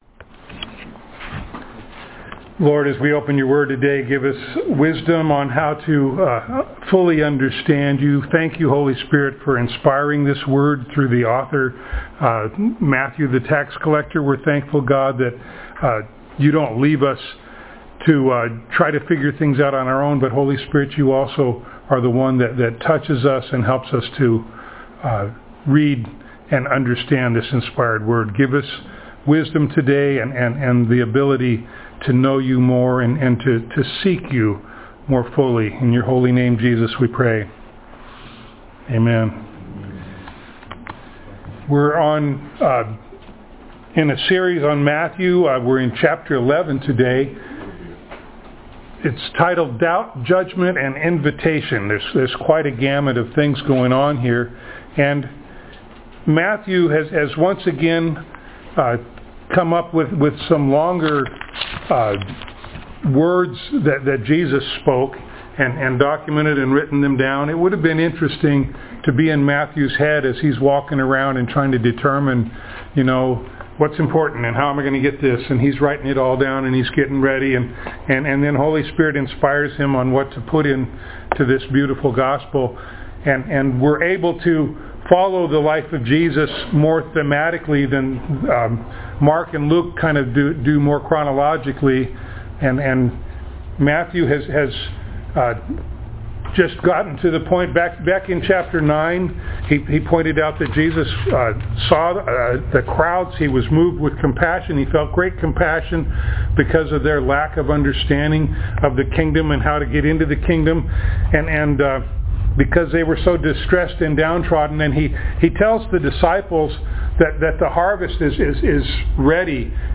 Matthew Passage: Matthew 11:1-30, Isaiah 35:4-6, Isaiah 61:1 Service Type: Sunday Morning